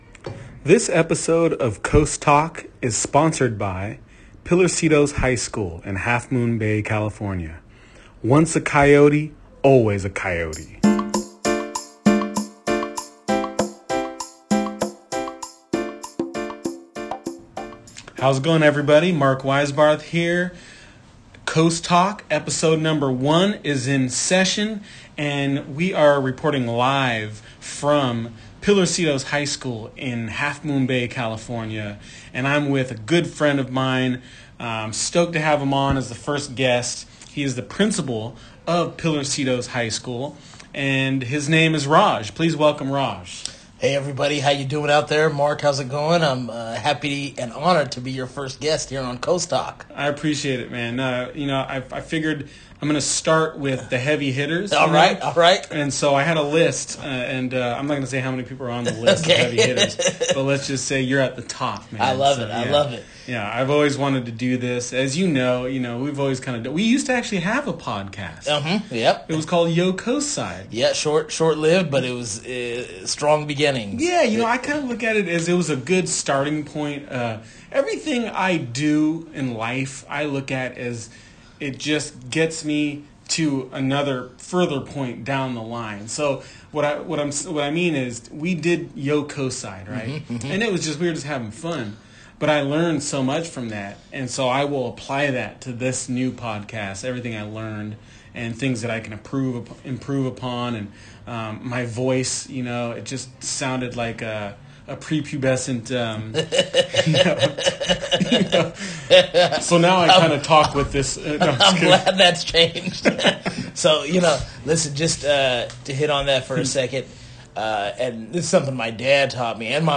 I enjoyed these two friends talking about growing up on the coast, the coastal educational system, raising their own kids knowing what they know